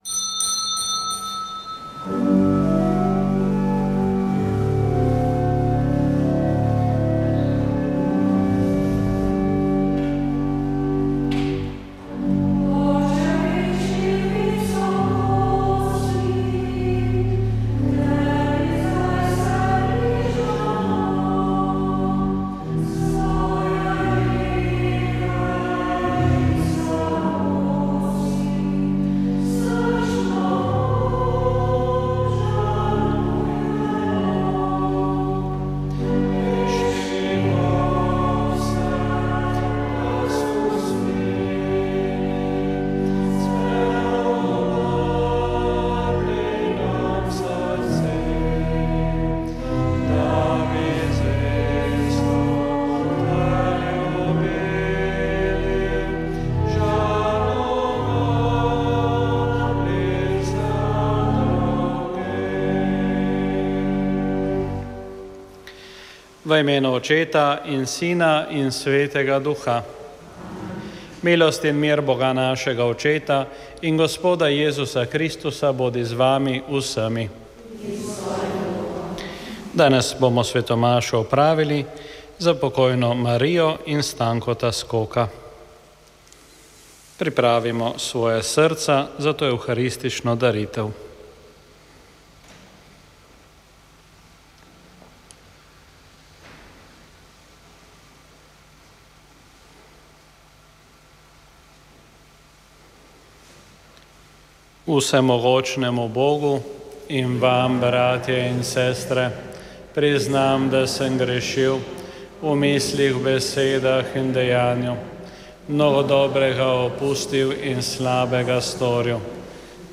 Sv. maša iz župnijske cerkve sv. Jožefa in sv. Barbare iz Idrije 11. 11.
pel pa Župnijski pevski zbor sv. Urbana iz Godoviča.